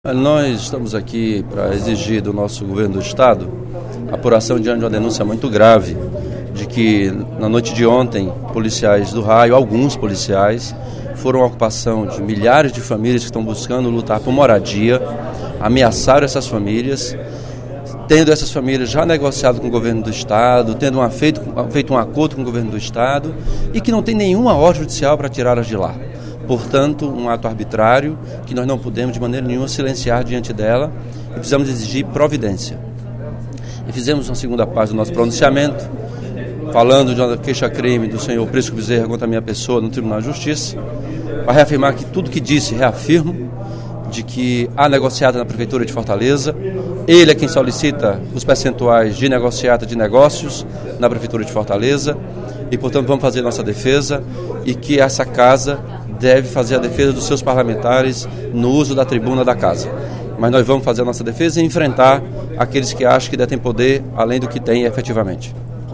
O deputado Elmano Freitas (PT) pediu, nesta quinta-feira (09/06), durante o primeiro expediente da sessão plenária, a apuração da ação dos policiais do Raio contra as famílias da ocupação Povo Sem Medo, que estão em um terreno do Governo do Estado localizado no Bom Jardim.